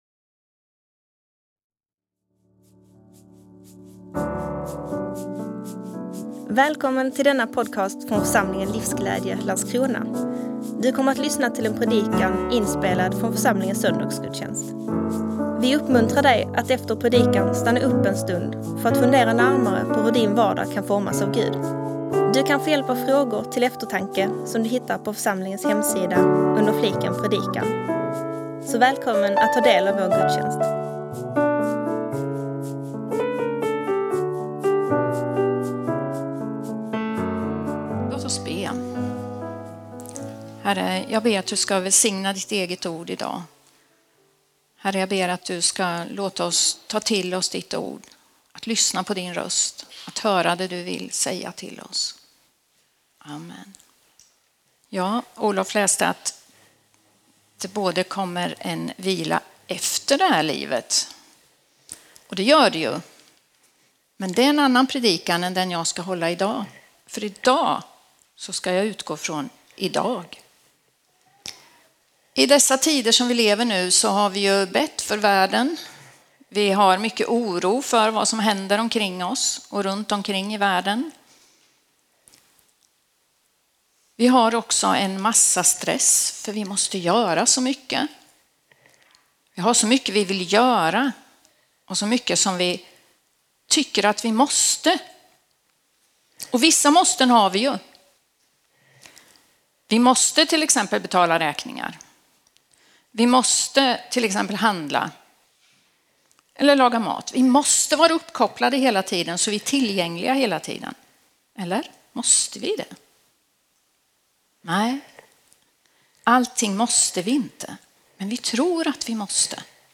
predikar om Guds vila..